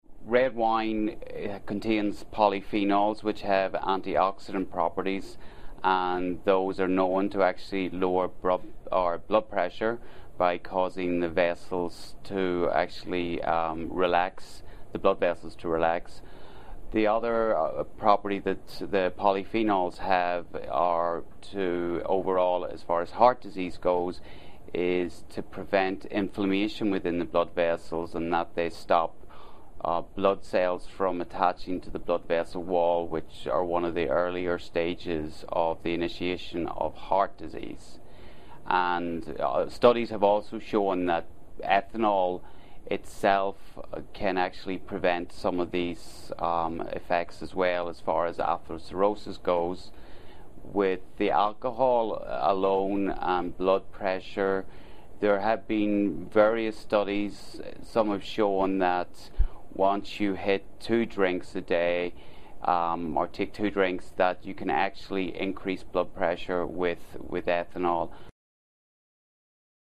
访谈录 2012-09-24&09-26 专家访谈：红酒有益健康 听力文件下载—在线英语听力室